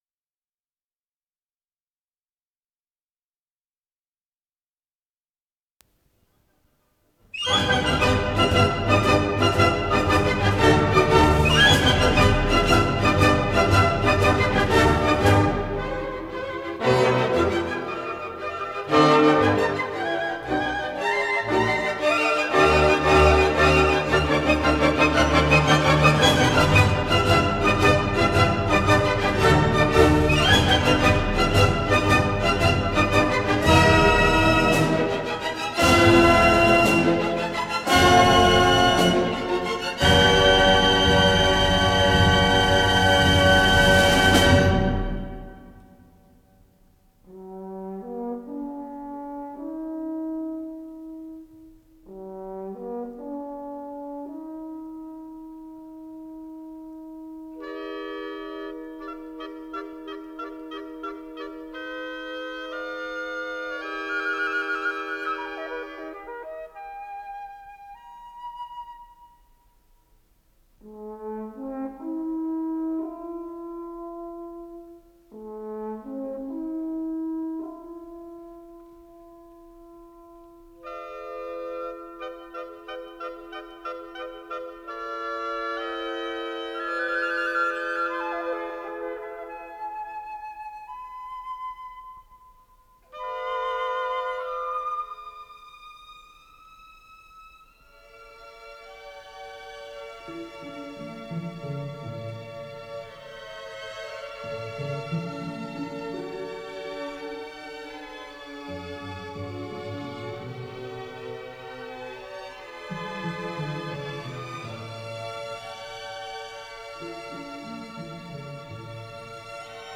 с профессиональной магнитной ленты
ПодзаголовокСоль мажор
ВариантДубль моно